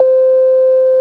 call_blocked_sepura.wav